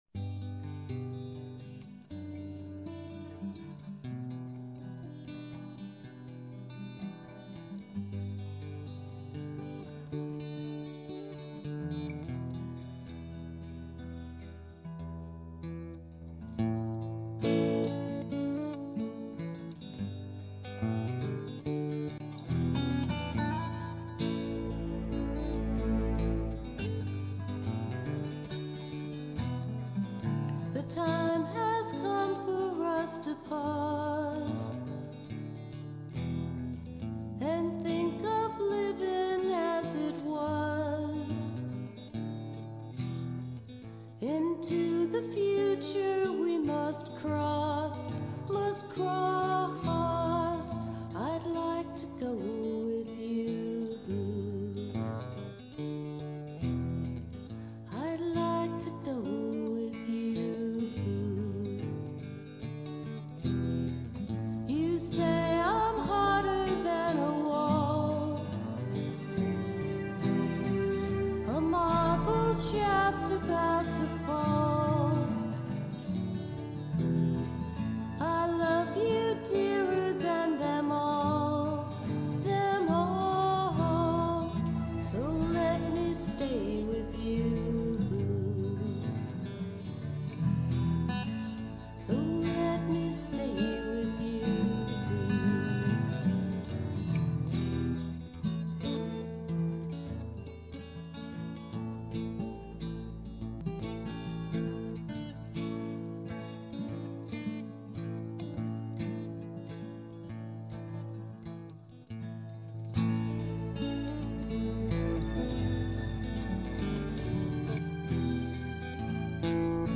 Vocals
Takemine x 2, Keyboards